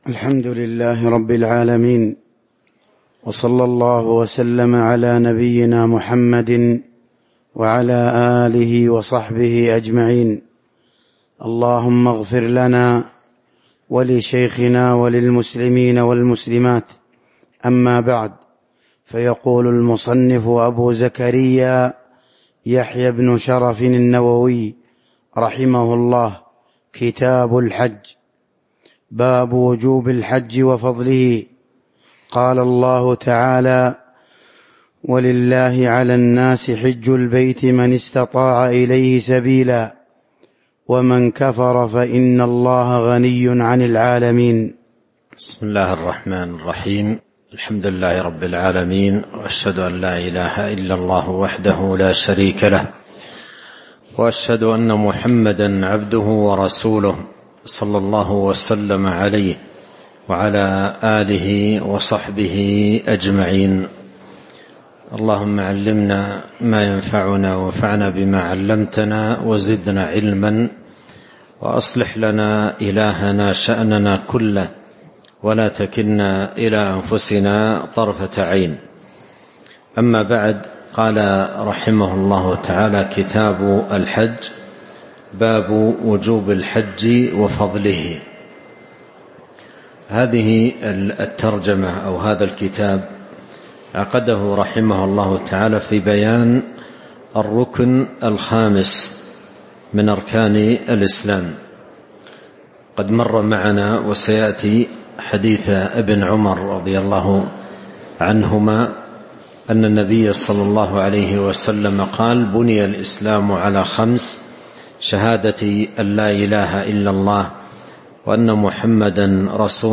تاريخ النشر ١٤ جمادى الآخرة ١٤٤٥ هـ المكان: المسجد النبوي الشيخ